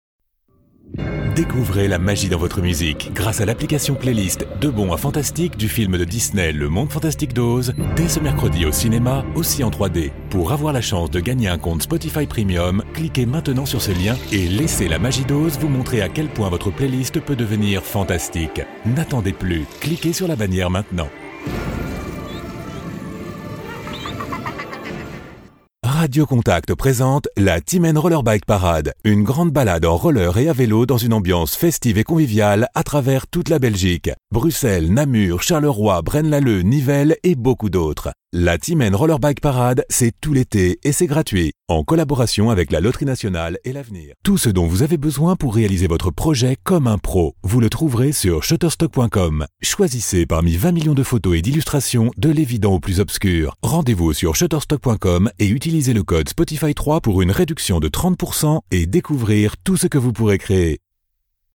FR EU XS COM 01 Commercials Male French (European)